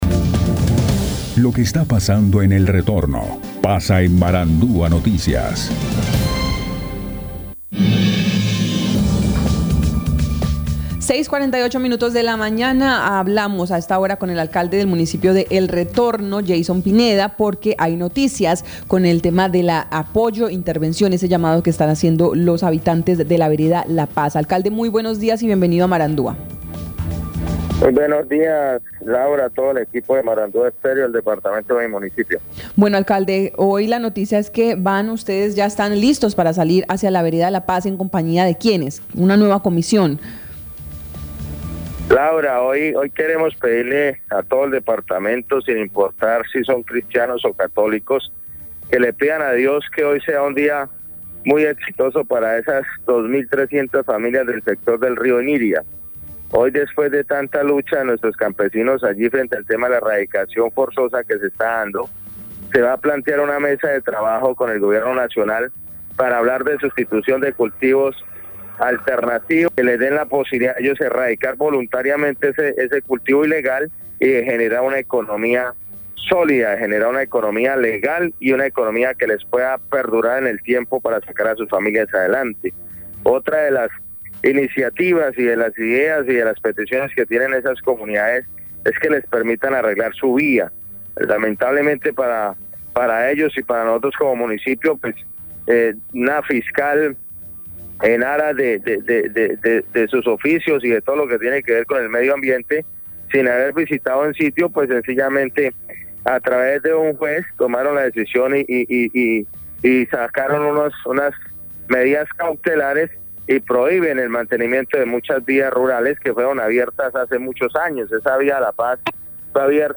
Escuche a Yeison Pineda, alcalde de El Retorno, Guaviare.
En diálogo con Marandua Noticias, el alcalde de El Retorno, Yeison Pineda, aseguró que es claro lo que solicitan los campesinos de esos sectores de la Paz, Salto Gloria, Kuwait y otras veredas cercanas, proyecto alternativos que les garanticen mejorar la calidad de vida con el acompañamiento del Estado.